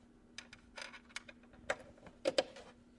电脑声音 " Jack in
描述：将千斤顶连接器放入PC内
标签： 杰克 计算机 PC 音响